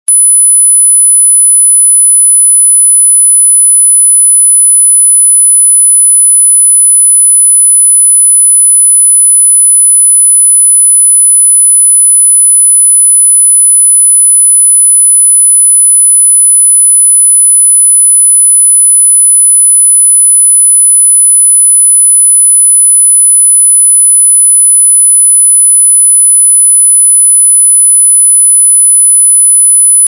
Звуки отпугивания комаров
Подборка включает различные частоты и тональности, доказавшие свою эффективность против мошек и комаров.
Громкий писк включаете и убегаете